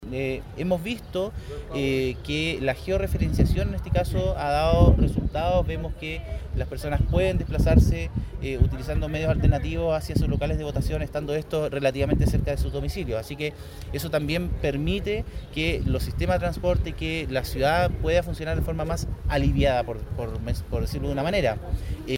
Por su parte, el seremi de Transportes, Pablo Joost, destacó el beneficio que representa para las personas la georeferenciación, lo que permite que puedan sufragar en lugares cercanos a sus domicilios.